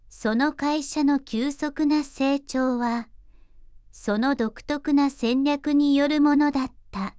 voicevox-voice-corpus / ita-corpus /九州そら_ノーマル /EMOTION100_025.wav